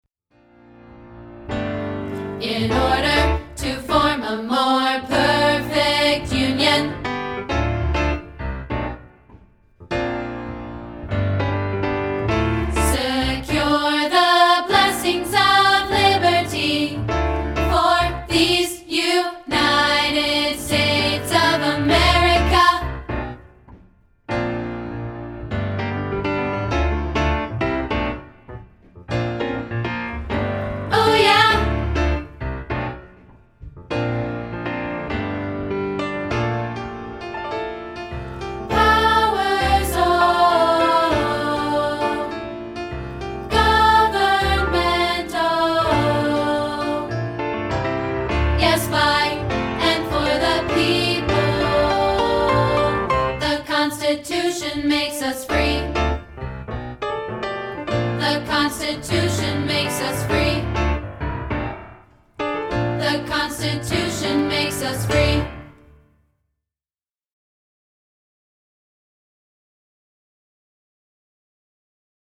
This is a rehearsal track of part 2, isolated.